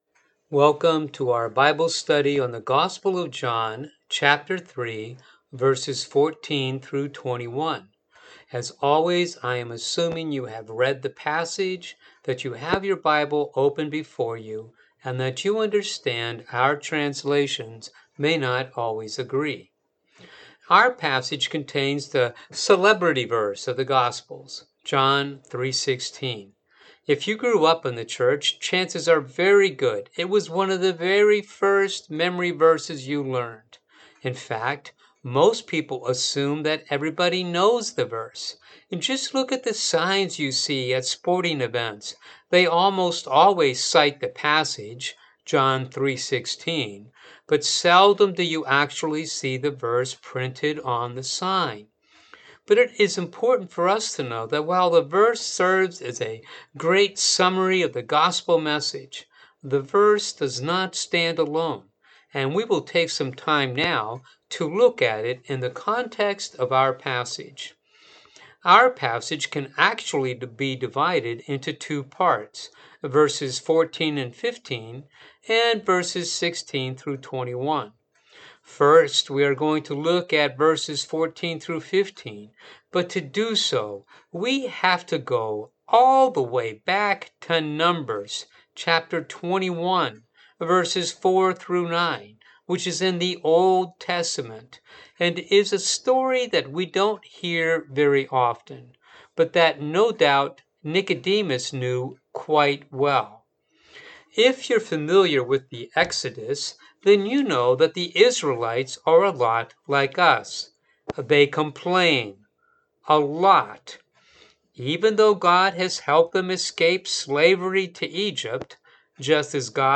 Bible Study For The March 14 Sunday Service